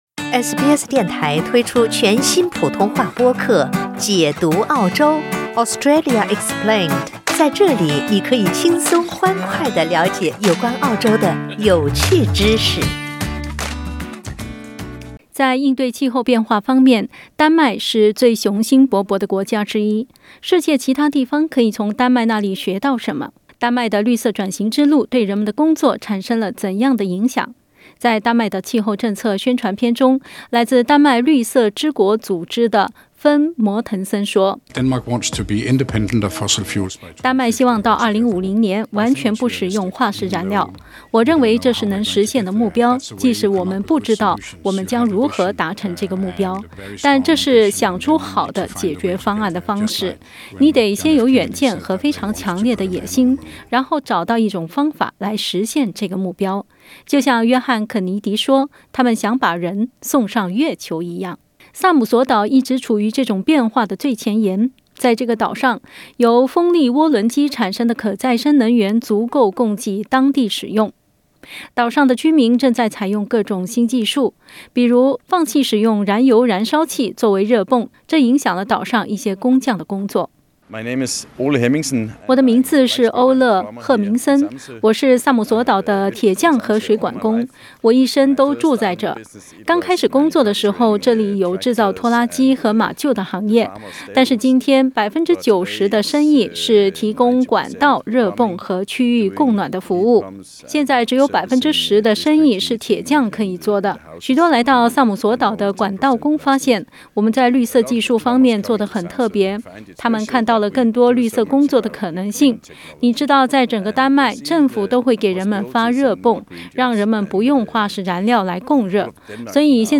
丹麦政府计划到2050年全面停用化石燃料。(点击图片收听报道）